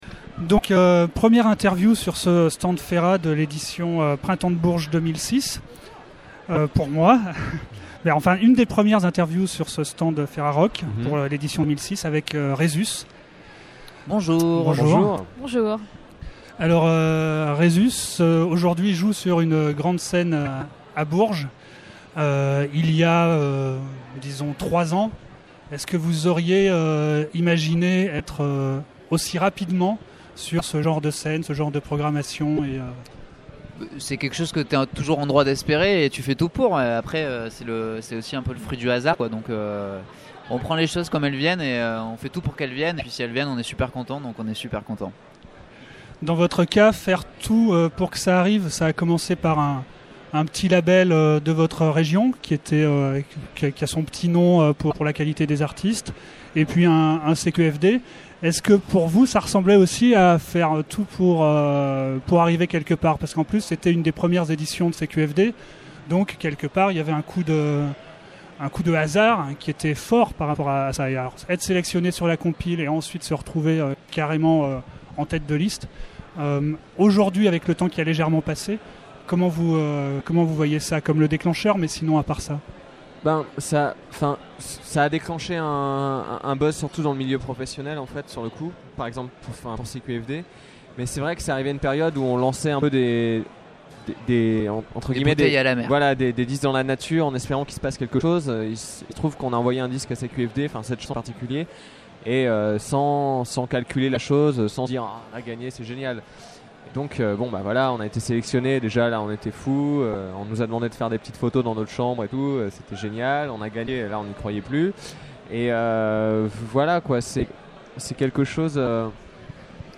Rhesus Festival du Printemps de Bourges 2006 : 40 Interviews à écouter !